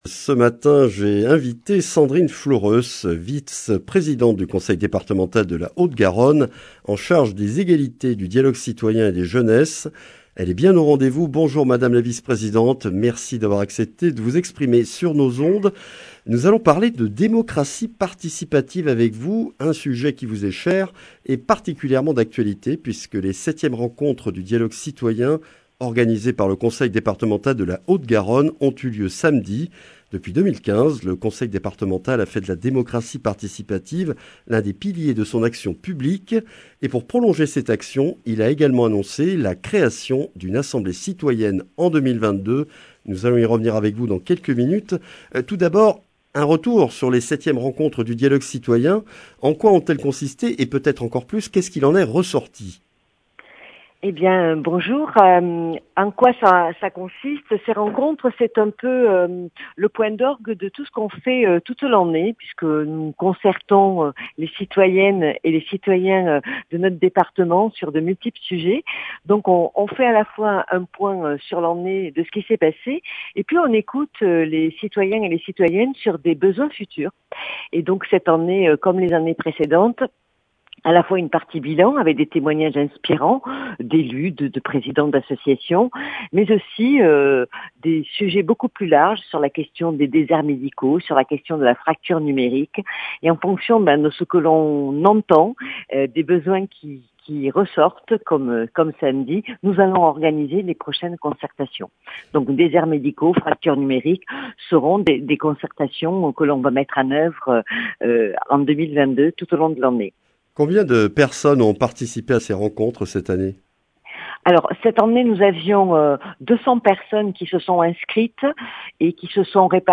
Sandrine Floureusses, vice-présidente du Conseil départemental de la Haute-Garonne en charge des Égalités, du Dialogue citoyen et des Jeunesses, est l’invitée de cette matinale après l’annonce de la création d’une Assemblée citoyenne de la Haute-Garonne par le Conseil départemental. Une nouvelle instance de démocratie participative qui comptera 162 membres (6 par canton) à laquelle tout citoyen haut-garonnais souhaitant y participer et âgé de plus de 18 ans peut s’inscrire jusqu’à fin juin.